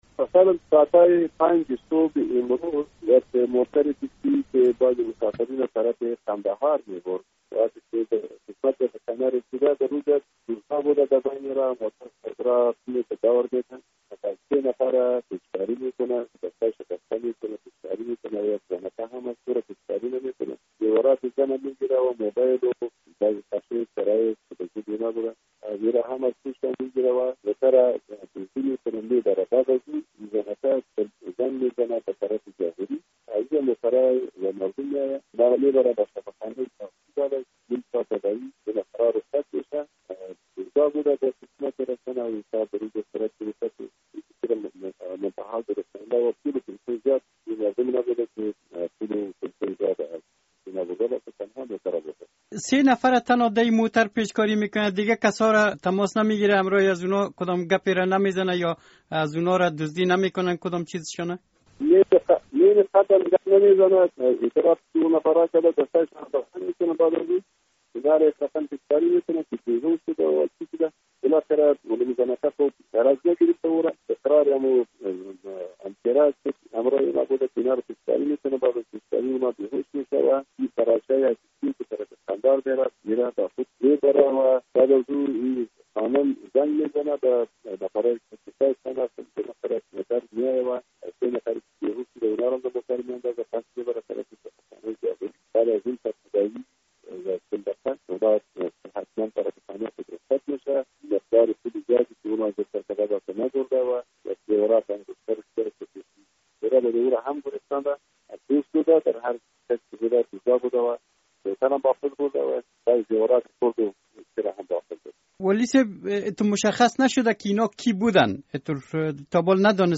مصاحبه - صدا
محمد موسی اکبر زاده سرپرست ولایت غزنی